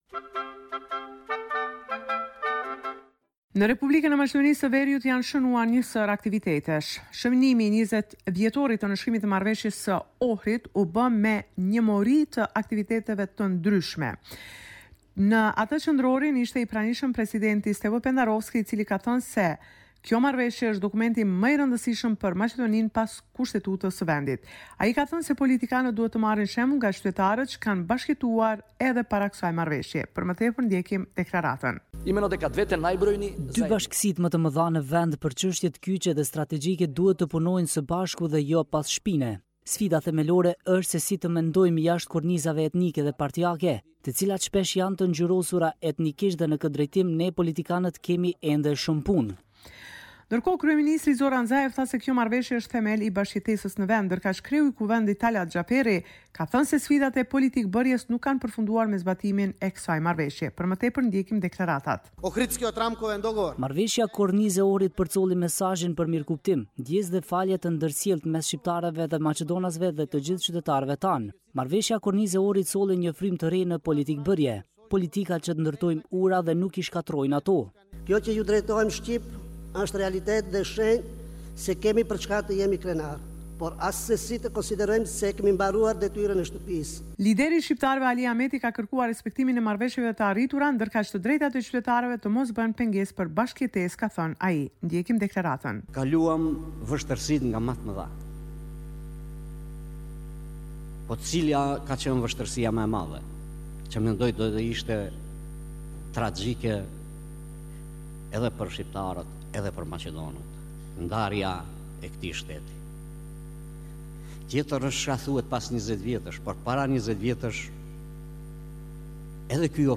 Raporti me te rejat me te fundit nga Maqedonia e Veriut.